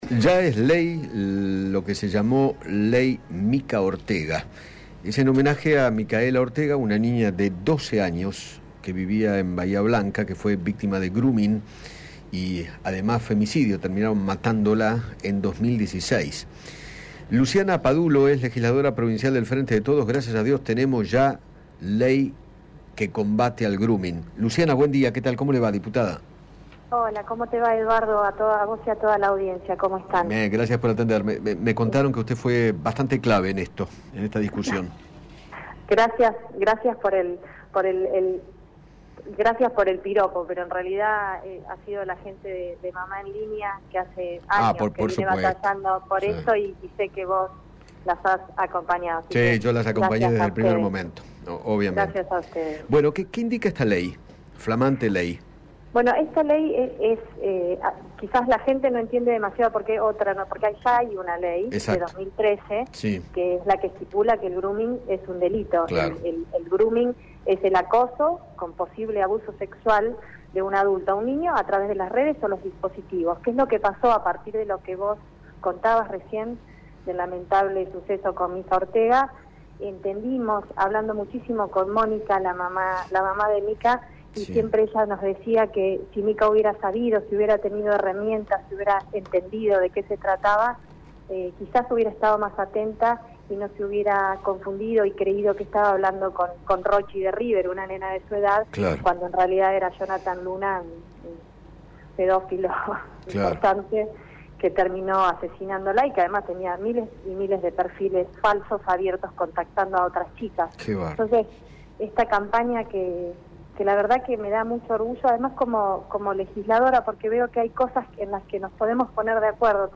Luciana Padulo, diputada del Frente de Todos e impulsora del proyecto, dialogó con Eduardo Feinmann sobre los alcances de la nueva ley que establece la creación del Programa Nacional de Prevención y Concientización del grooming o ciberacoso contra niñas, niños y adolescentes.